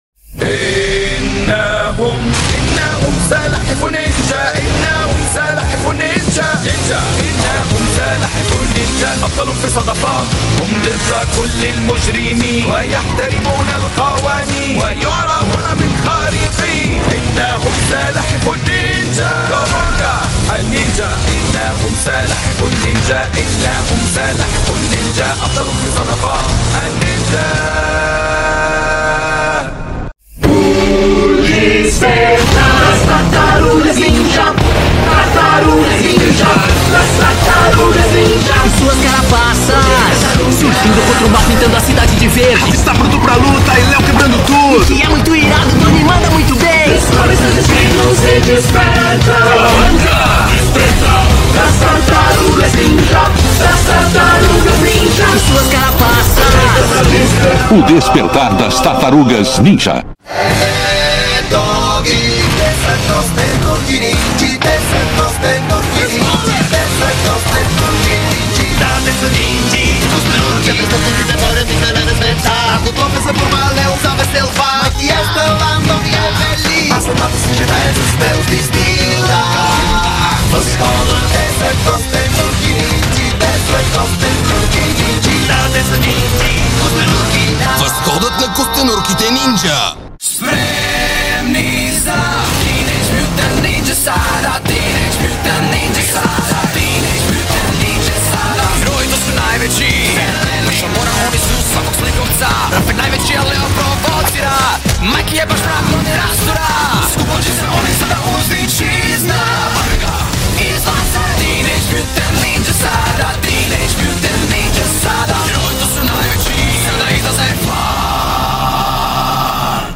Multilanguage (Dubs Only, 28 Languages)